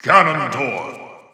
The announcer saying Ganondorf's name in English and Japanese releases of Super Smash Bros. 4 and Super Smash Bros. Ultimate.
Ganondorf_English_Announcer_SSB4-SSBU.wav